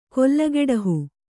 ♪ kollageḍahu